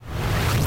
lasercharge2.ogg